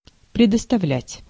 Ääntäminen
IPA: [ɔ.fʁiʁ]